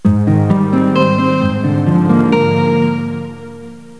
Guitar1.snd